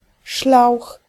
Ääntäminen
Synonyymit jargon serpent buis groepstaal Ääntäminen : IPA: [slɑŋ] Haettu sana löytyi näillä lähdekielillä: hollanti Käännös Ääninäyte 1. wąż {m} 2. szlauch {f} Suku: f .